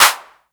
Clap
Original creative-commons licensed sounds for DJ's and music producers, recorded with high quality studio microphones.
Clap Sound F Key 26.wav
clap-sound-f-key-26-gsh.wav